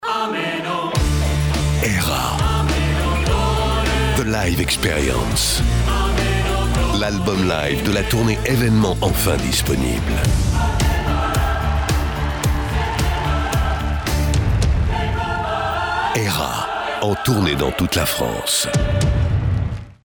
Spot Tournée ERA :
Era-spot-radio-tournee-2022-rk-m.mp3